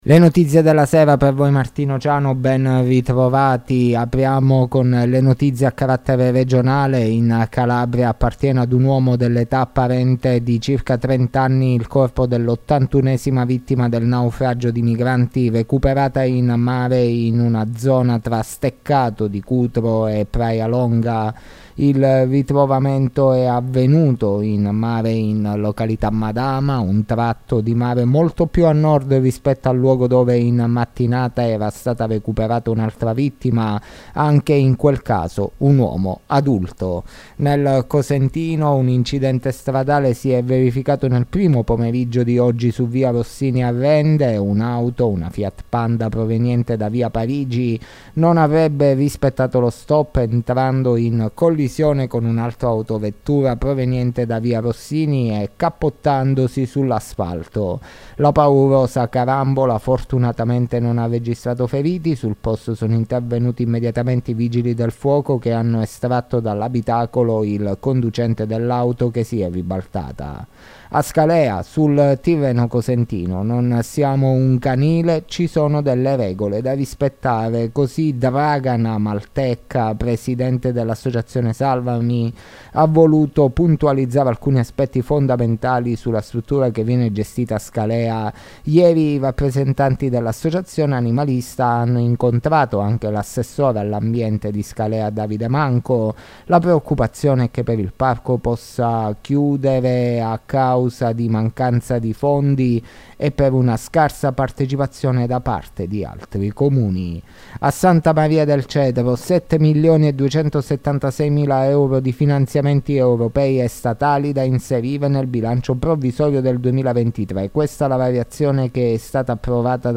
LE NOTIZIE DELLA SERA DI MARTEDì 14 MARZO 2023